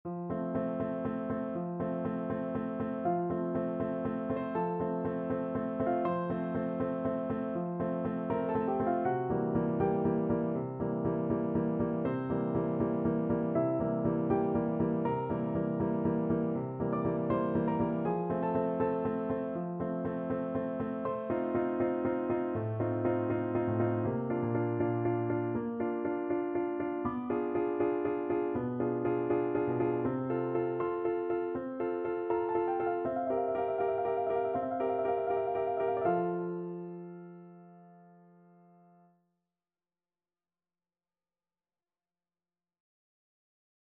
Free Sheet music for Piano Four Hands (Piano Duet)
4/4 (View more 4/4 Music)
Andante
Classical (View more Classical Piano Duet Music)